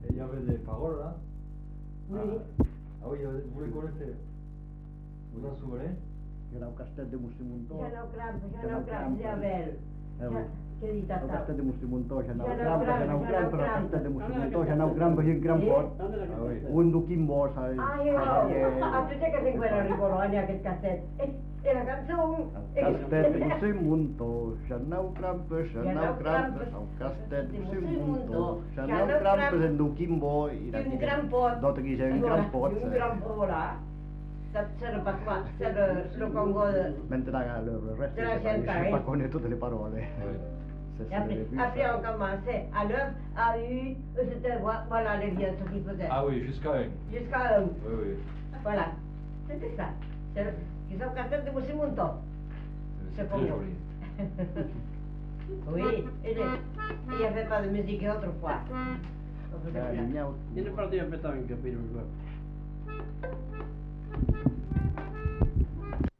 Lieu : Saint-Justin
Genre : chant
Effectif : 1
Type de voix : voix d'homme
Production du son : chanté
Danse : congo